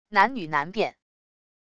男女难辨wav音频